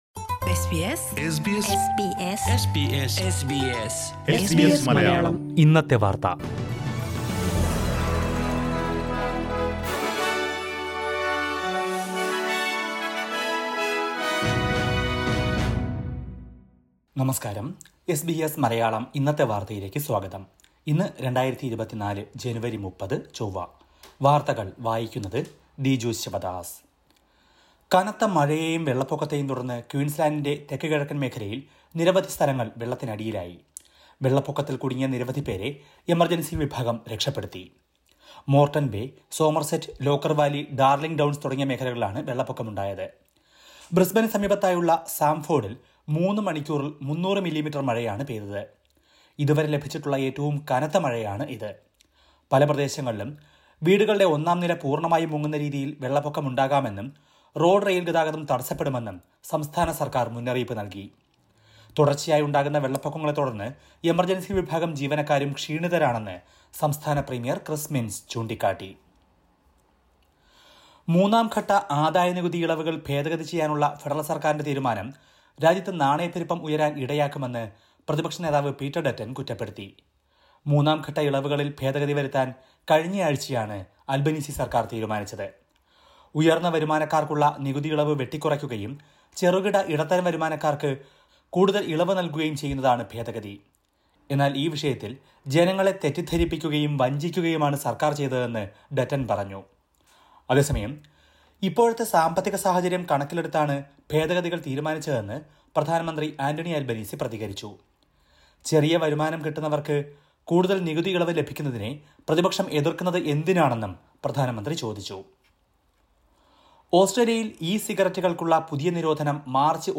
2024 ജനുവരി 30ലെ ഓസ്‌ട്രേലിയയിലെ ഏറ്റവും പ്രധാന വാര്‍ത്തകള്‍ കേള്‍ക്കാം...